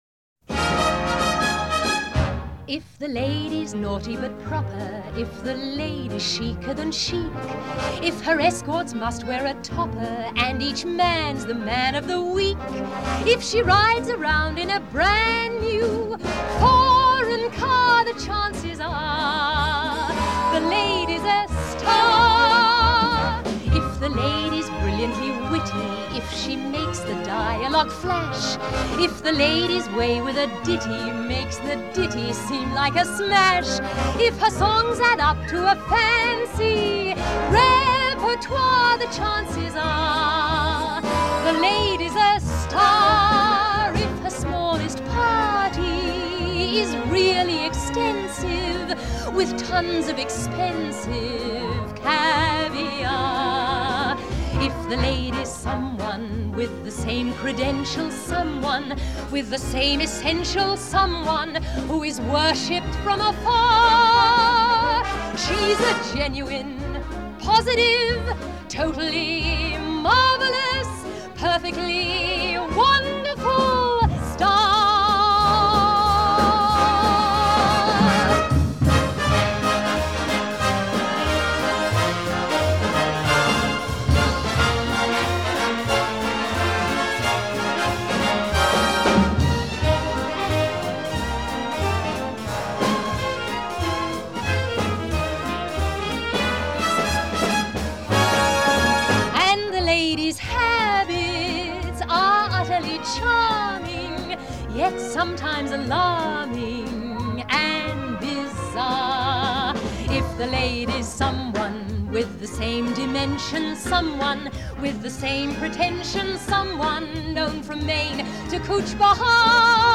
1968   Genre: Musical   Artist